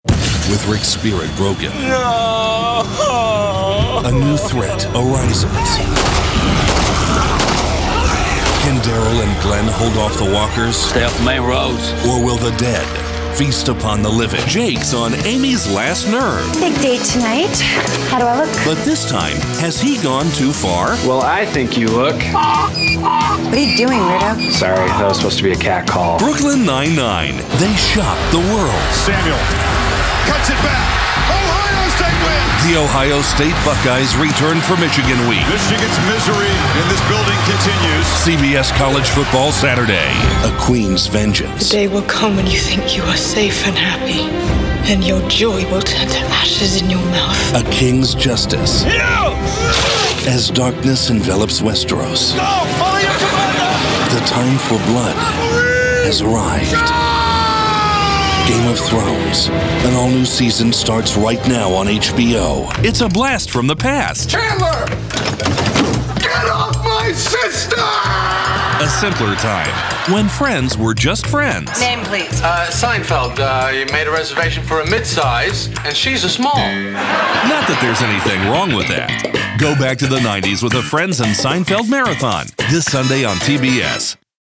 standard us
promos